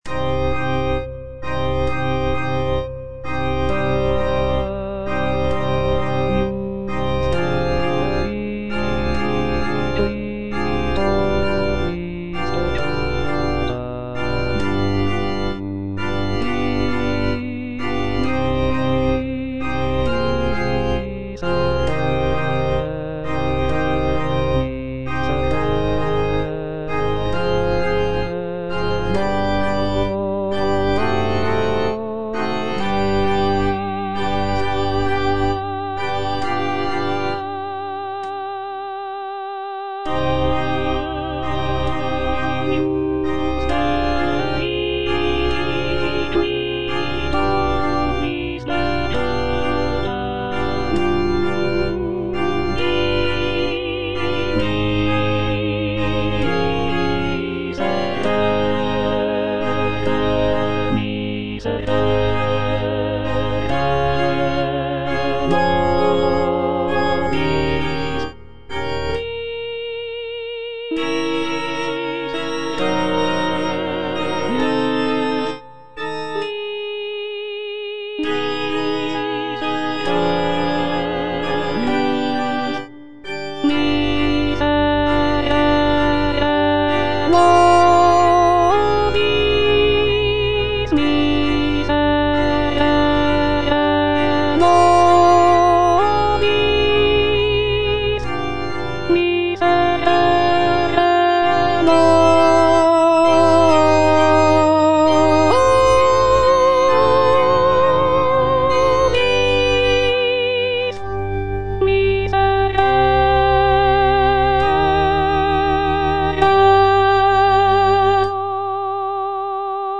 C.M. VON WEBER - MISSA SANCTA NO.1 Agnus Dei - Alto (Voice with metronome) Ads stop: auto-stop
"Missa sancta no. 1" by Carl Maria von Weber is a sacred choral work composed in 1818.